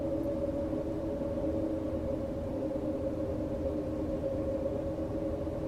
白噪声房间里1.wav